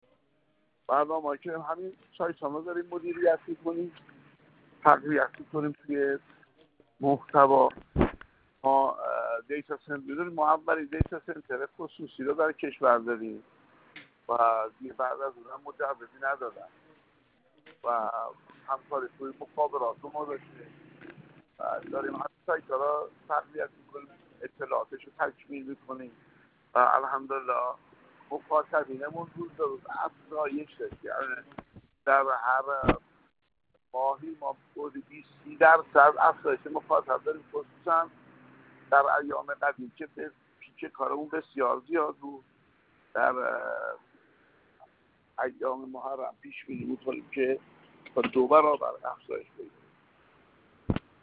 اصفهان در گفت‌وگو با ایکنا با اشاره به اینکه فعالیت مؤسسه از سال 1384 آغاز شده است، در باره فعالیت مؤسسه، گفت